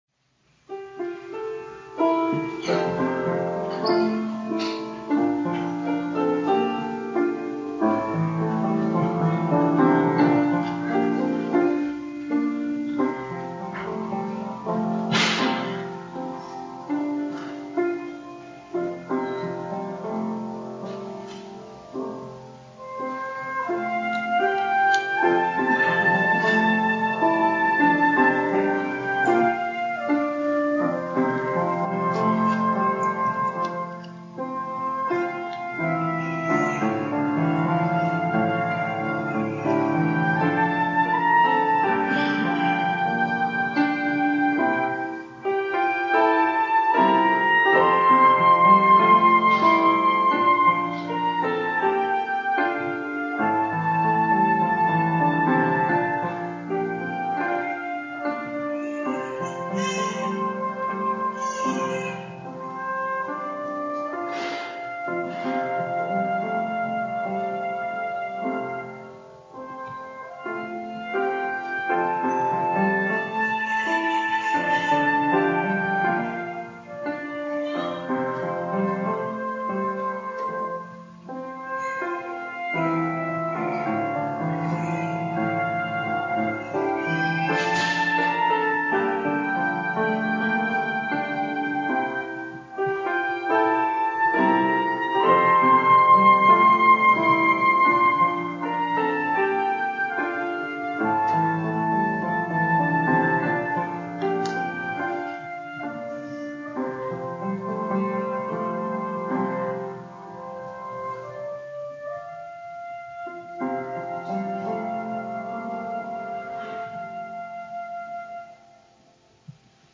Worship Service
Special Music
Sermon